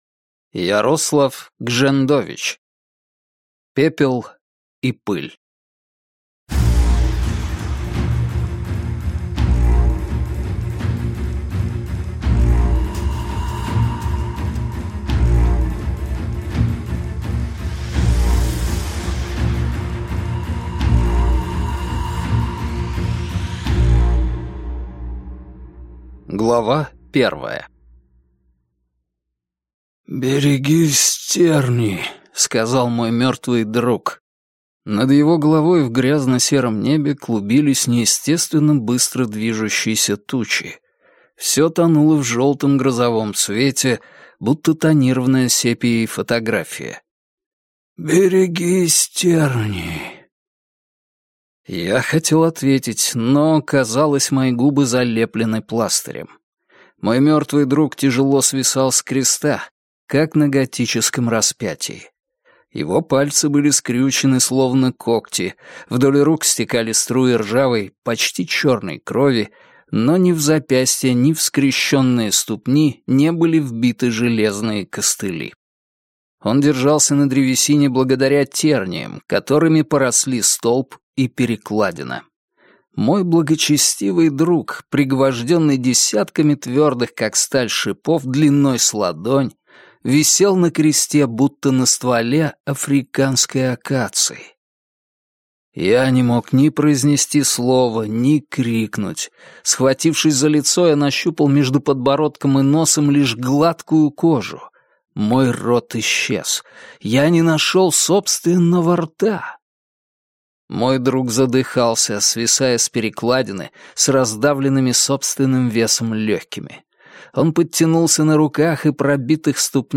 Аудиокнига Пепел и пыль | Библиотека аудиокниг